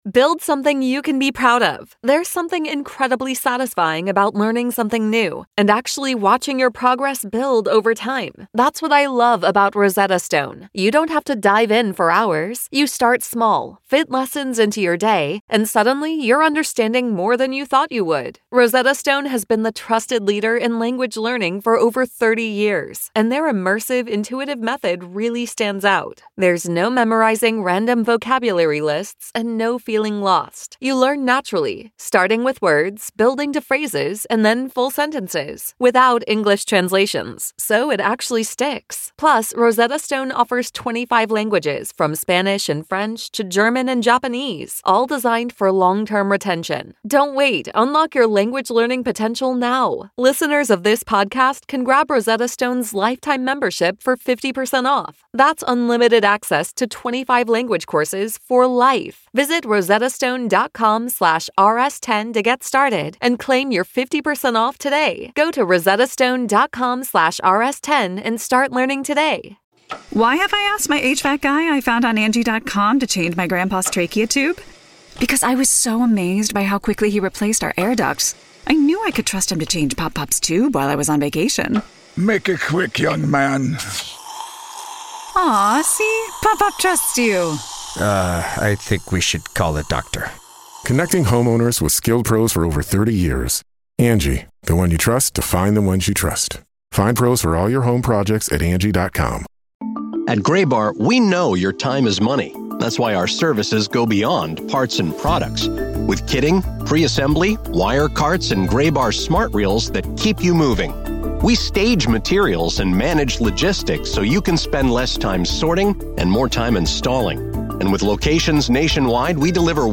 02-12-26 The Mason Crosby Show Interviews